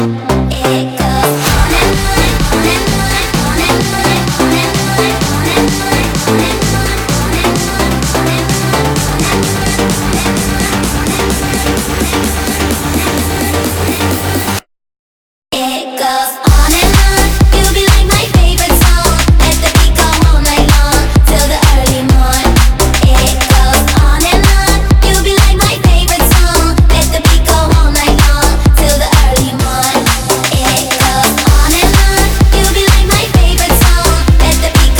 Жанр: Транс
# Trance